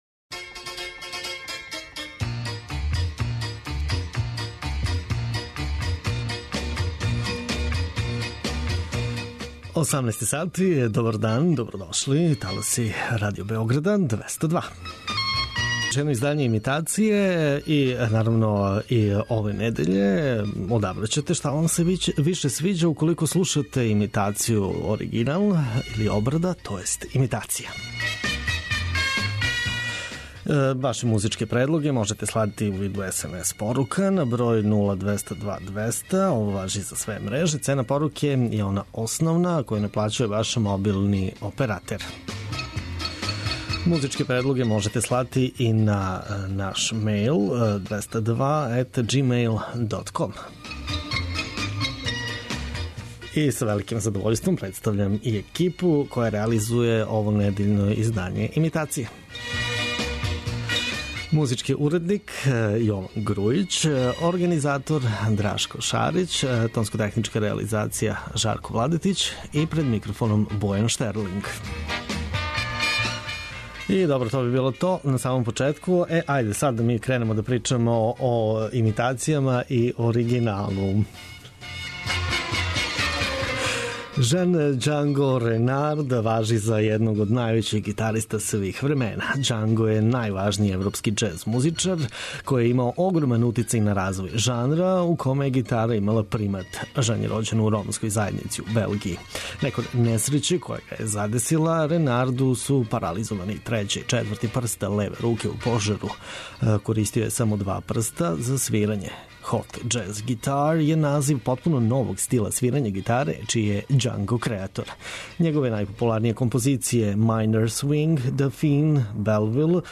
Имитација је емисија у којој се емитују обраде познатих хитова домаће и иностране музике.